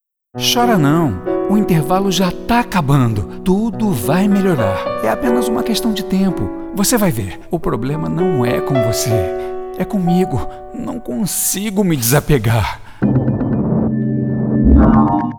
Masculino
Voz Jovem 00:15
• Tenho voz leve e versátil, e interpretação mais despojada.